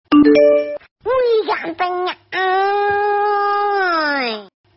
Notification icon Nada dering WA sebut nama suara Google
Kategori: Nada dering